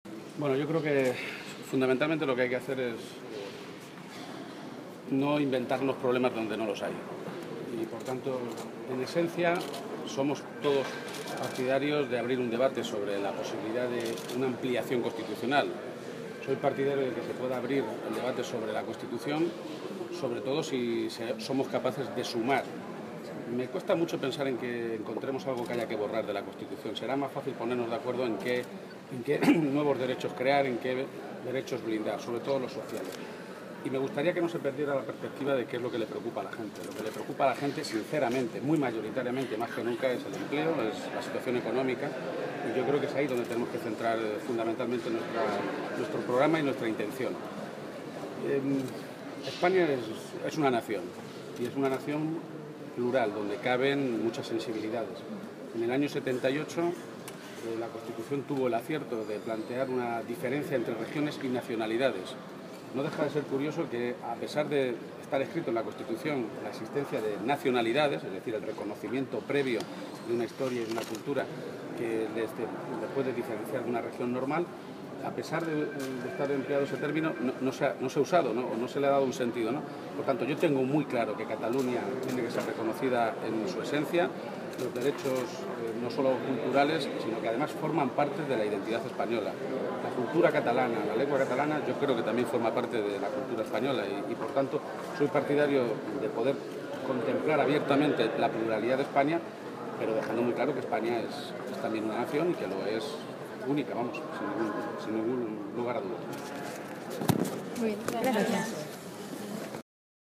García-Page se pronunciaba de esta manera esta mañana, en Sevilla, a preguntas de los medios de comunicación a su llegada a la reunión del Consejo de Política Federal del PSOE que reúne en la capital de Andalucía a todos los presidentes autonómicos y secretario generales regionales del Partido Socialista.